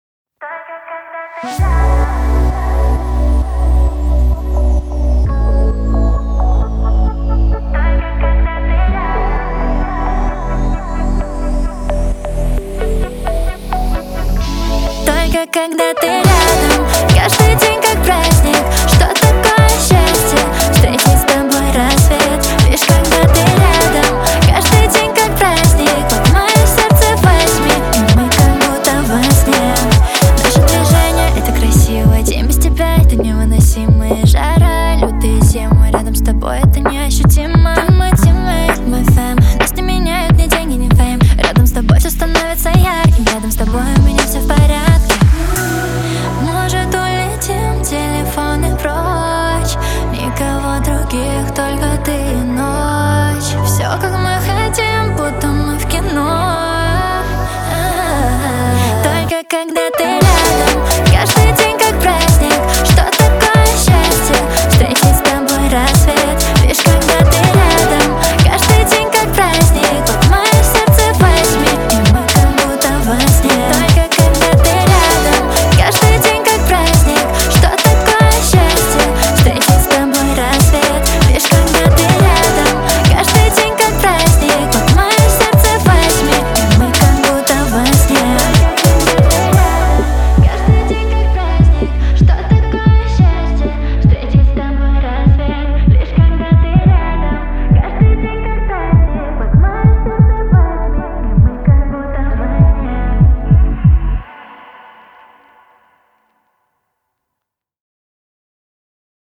Трек размещён в разделе Русские песни / Альтернатива.